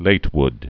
(lătwd)